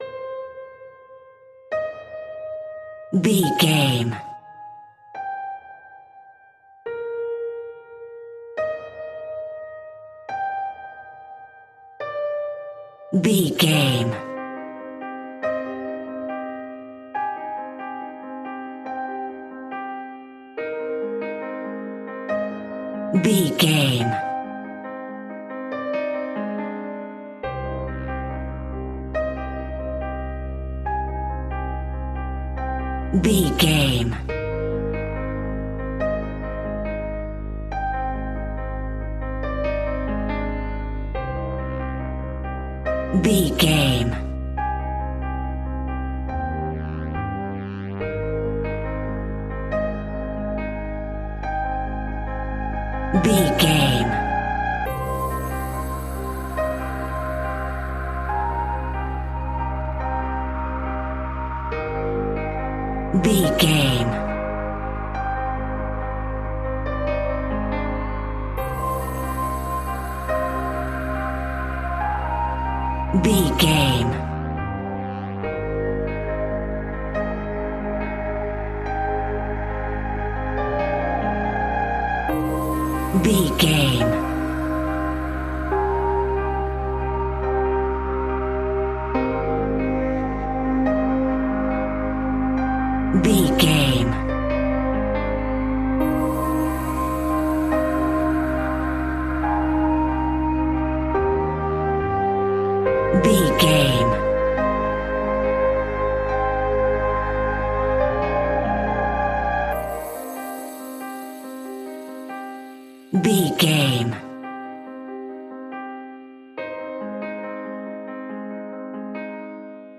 In-crescendo
Thriller
Aeolian/Minor
scary
tension
ominous
dark
haunting
eerie
piano
strings
synthesiser
percussion
drums
horror music